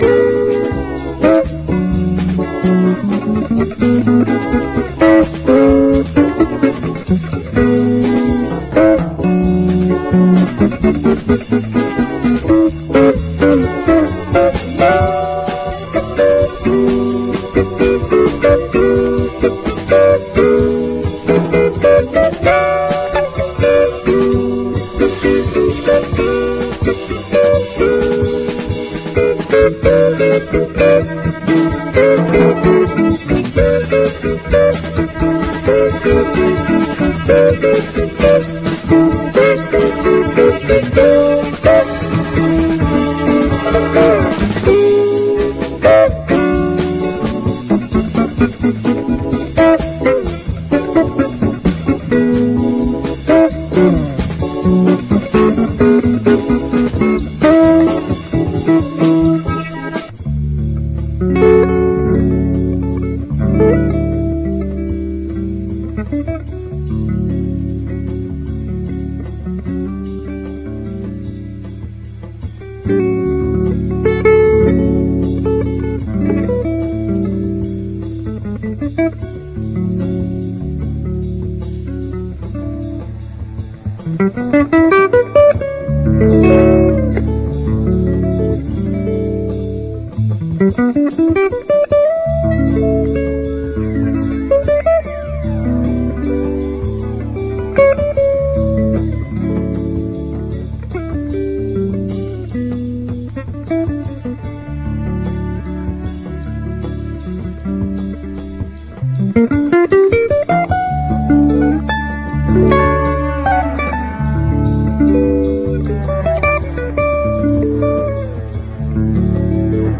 STEREO CHECK / DRUM BREAK / EASY LISTENING
和モノ・ブレイクビーツ/ドラムブレイク＆和ジャズ/ジャズファンクを収録したカッコイイ非売品レコード！